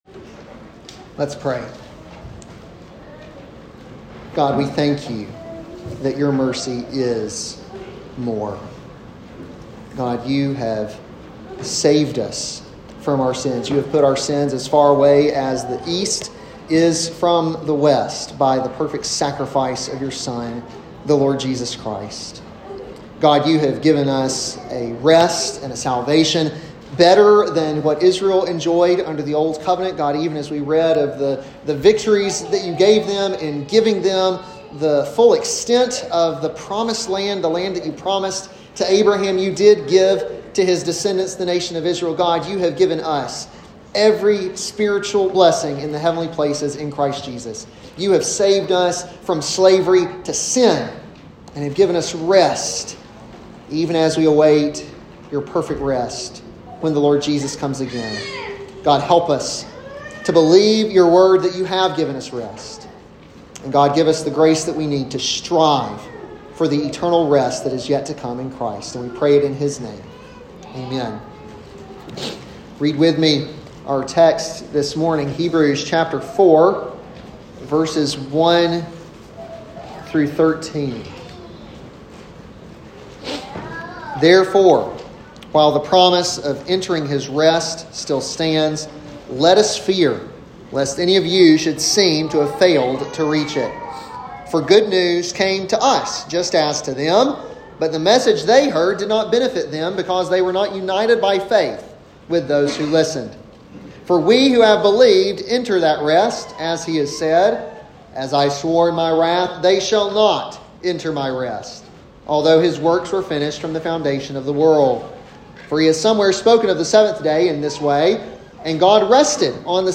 an expository sermon on Hebrews 4:1-13